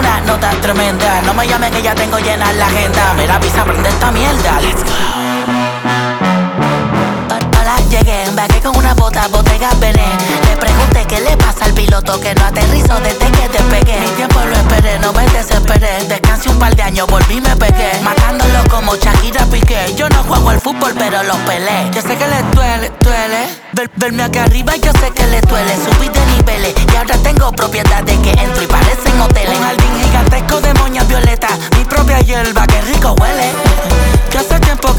Жанр: Латиноамериканская музыка
# Latino